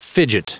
Prononciation du mot fidget en anglais (fichier audio)
Prononciation du mot : fidget